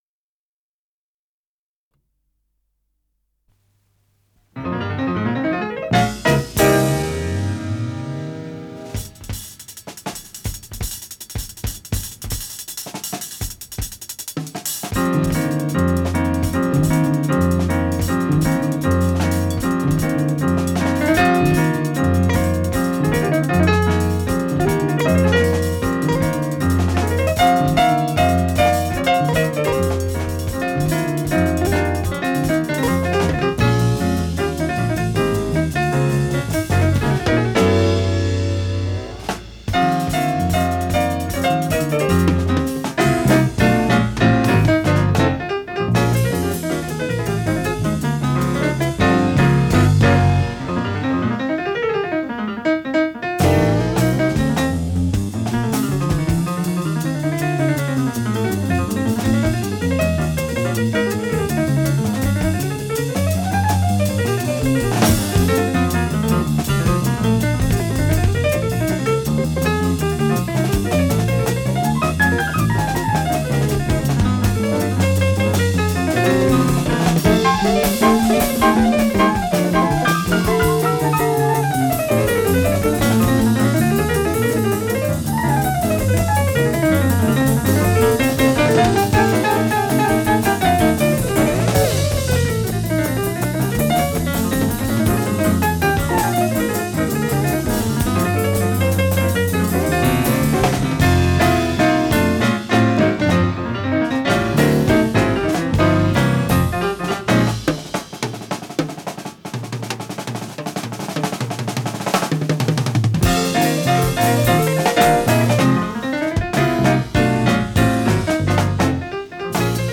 ПодзаголовокПьеса для инструментального ансамбля, соль мажор
фортепиано
гитара
бас-гитара
ударные
Скорость ленты38 см/с
ВариантДубль моно